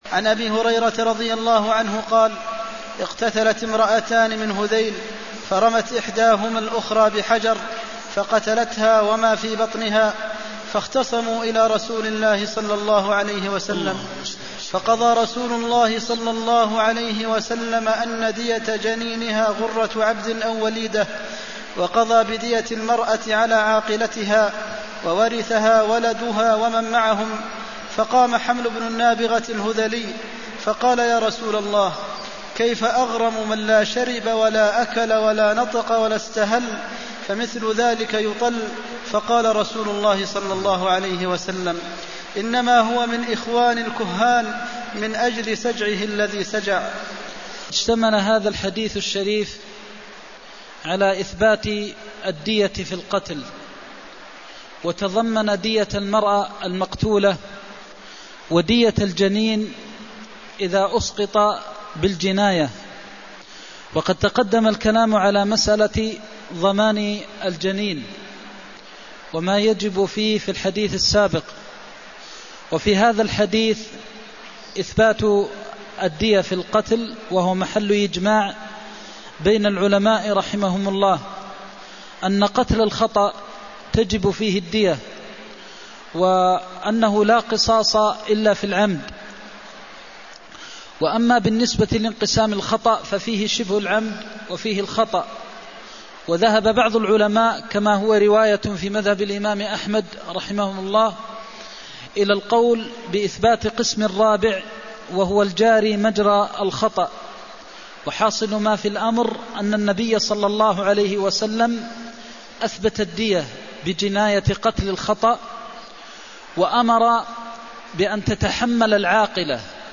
المكان: المسجد النبوي الشيخ: فضيلة الشيخ د. محمد بن محمد المختار فضيلة الشيخ د. محمد بن محمد المختار وقضى بدية المرأة على عاقلتها (325)